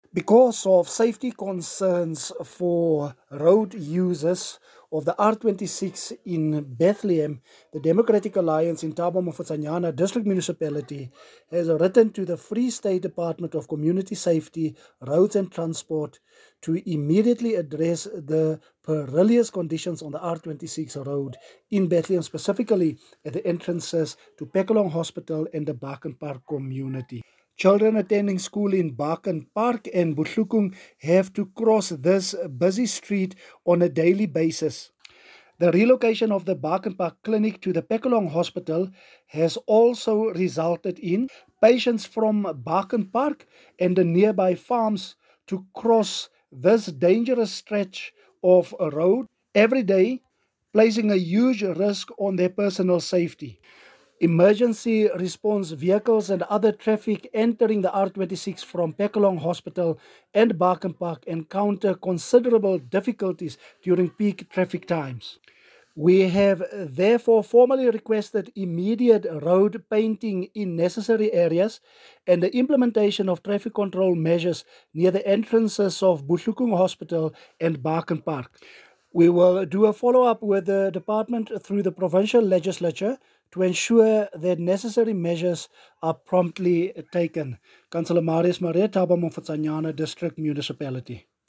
English soundbite by Cllr Marius Marais and